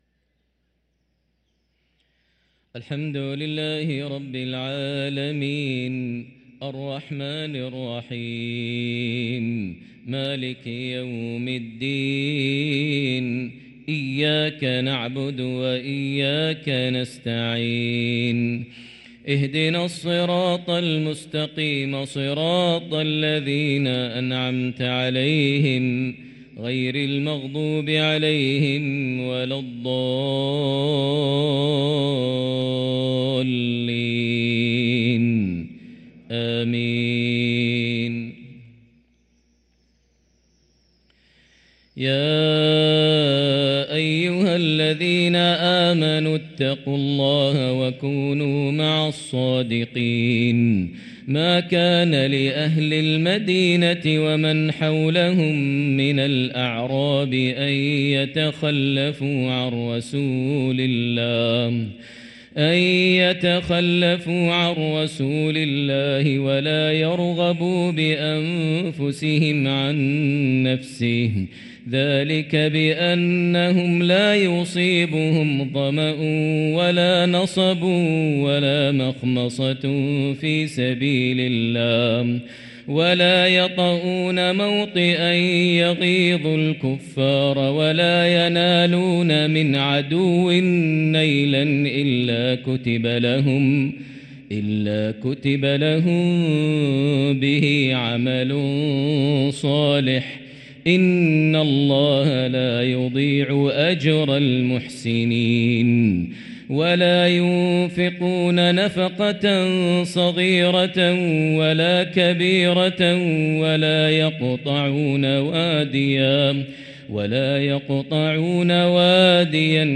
صلاة العشاء للقارئ ماهر المعيقلي 1 جمادي الآخر 1445 هـ